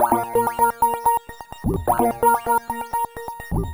Delhi Belly Bb 128.wav